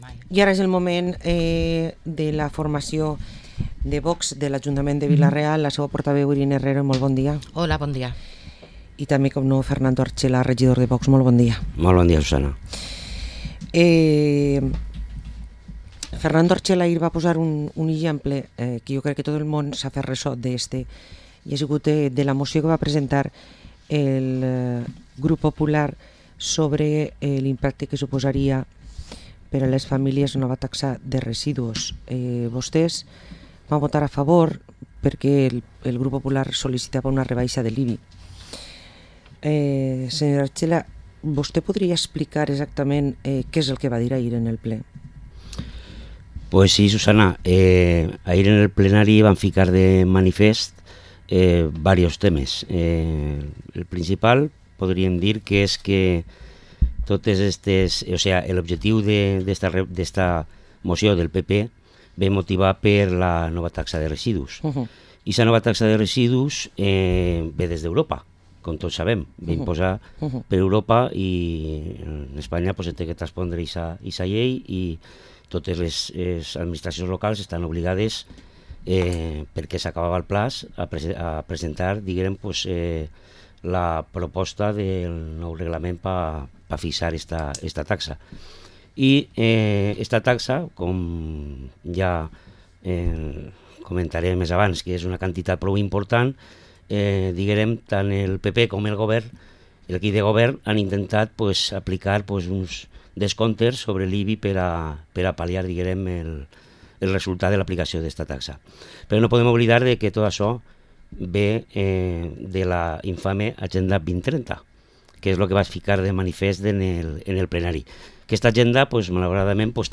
Irene Herrero i Fernando Archela regidors de VOX a l´Ajuntament de Vila-real, fan valoració del ple ordinari